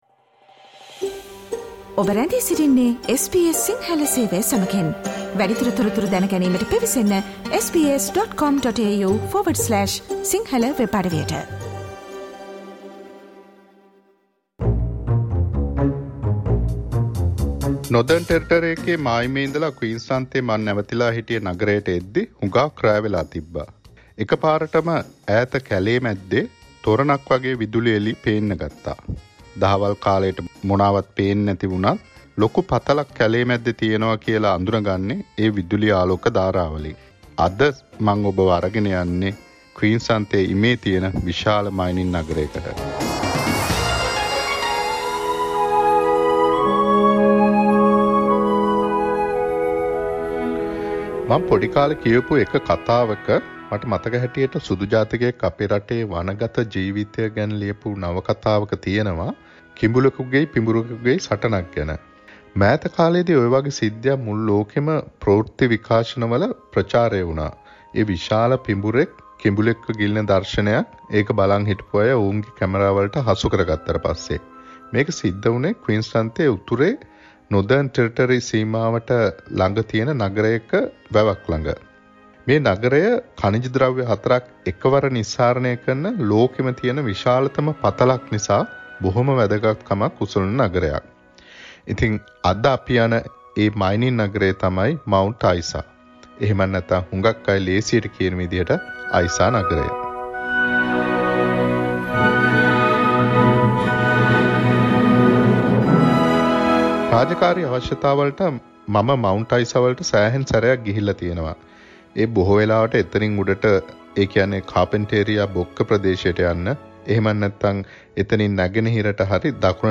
SBS Sinhala Radio journey to the mining city in Queensland_ Mount Isa